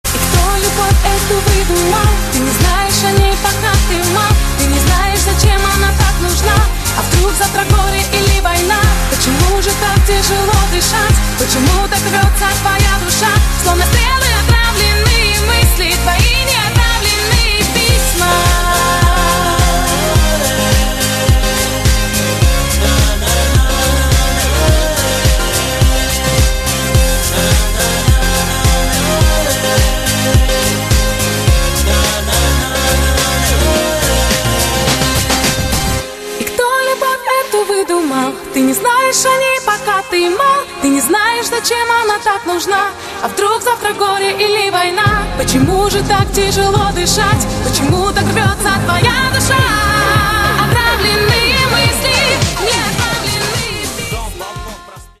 • Качество: 128, Stereo
громкие
женский вокал
Club House